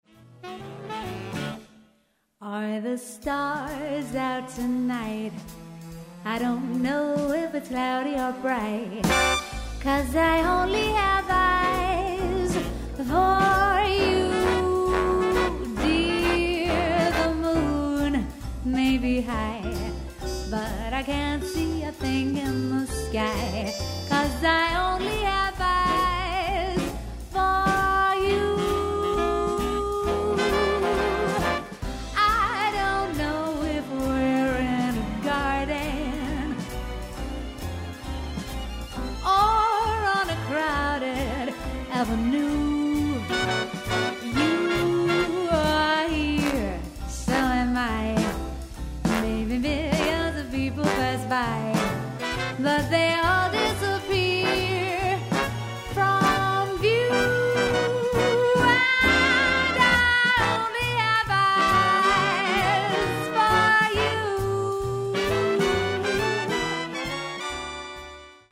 • Solo Singer